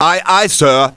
1 channel
windBalloonDrop.wav